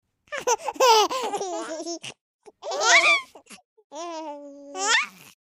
Elephant Playing With Turtle Sound Effects Free Download